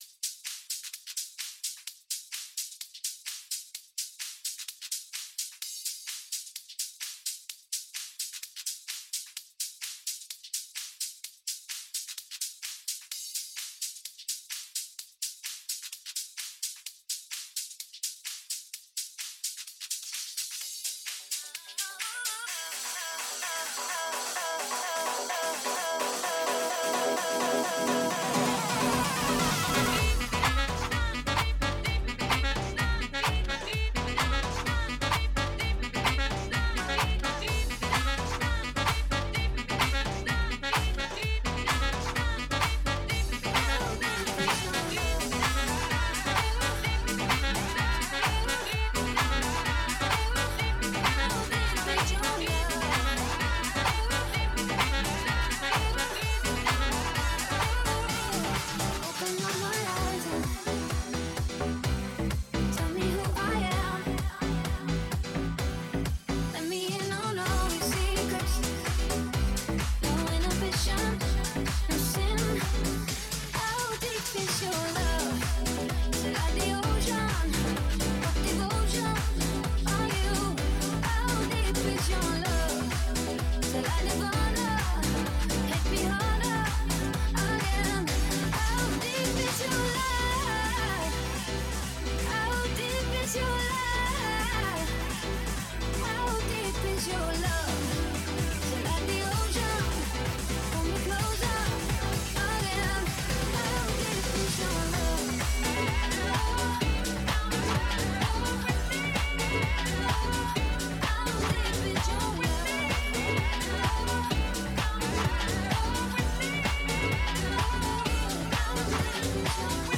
These are unauthorized bootlegs.
Genres: Hip Hop, Rock, Top 40